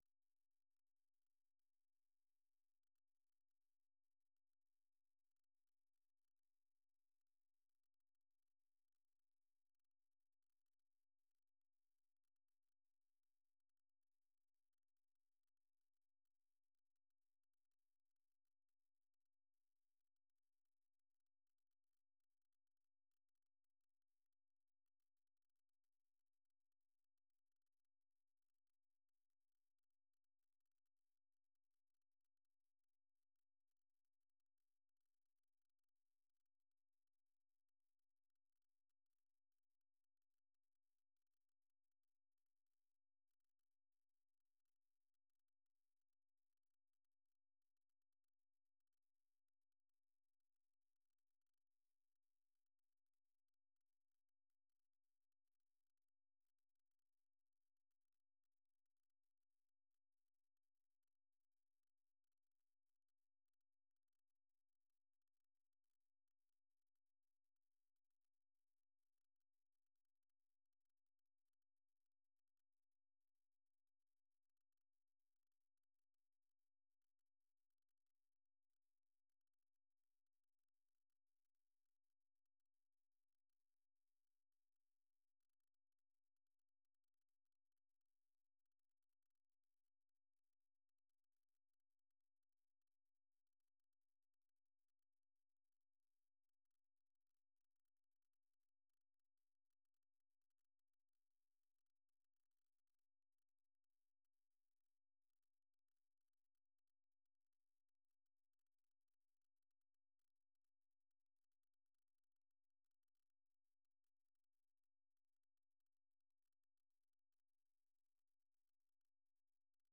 생방송 여기는 워싱턴입니다 저녁
세계 뉴스와 함께 미국의 모든 것을 소개하는 '생방송 여기는 워싱턴입니다', 저녁 방송입니다.